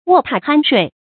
卧榻鼾睡 wò tà hān shuì 成语解释 别人在自己的床铺旁边呼呼大睡。